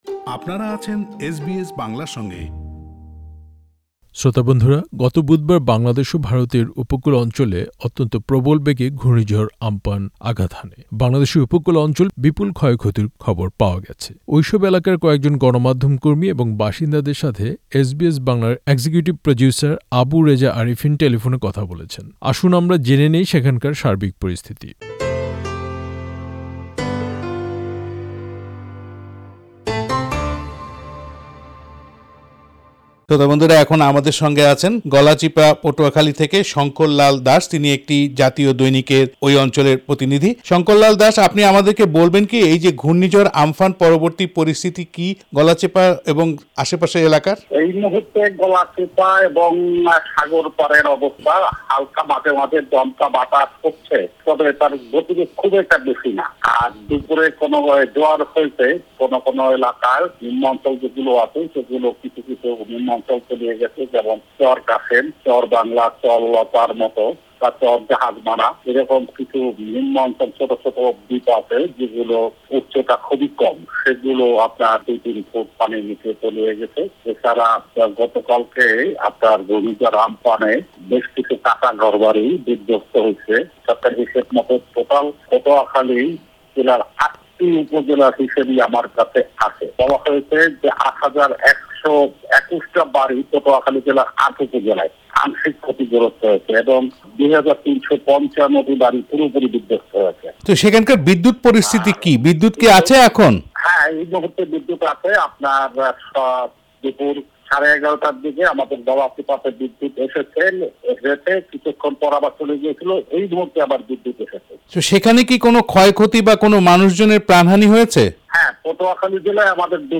টেলিফোনে কথা বলেছেন
পুরো সাক্ষাৎকার দু'টি শুনতে ওপরের অডিও প্লেয়ারটিতে ক্লিক করুন আরো পড়ুন: READ MORE ঘূর্ণিঝড় আম্ফানের আঘাত কি কাটিয়ে উঠতে পারবে বাংলাদেশ?